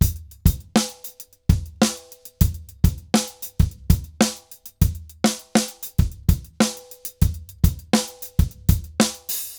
Drums_Candombe 100_1.wav